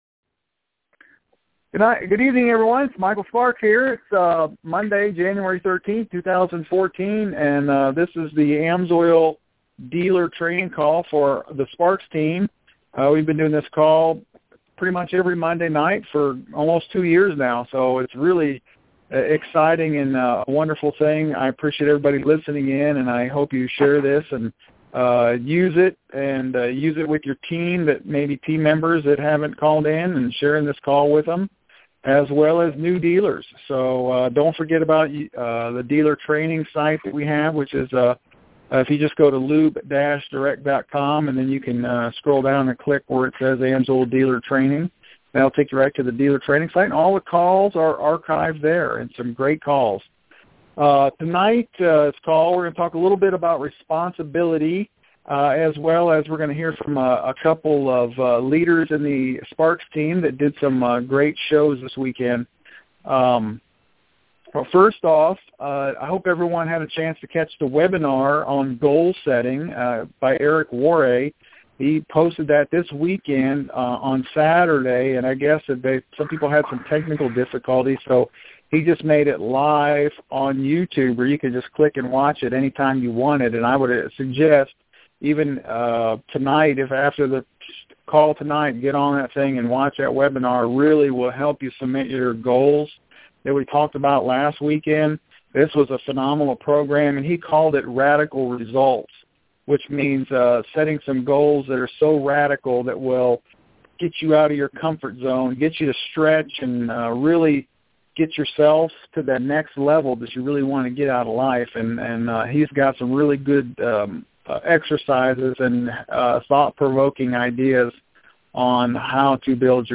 Sparks Team AMSOIL Dealer Training Call | January 13th, 2014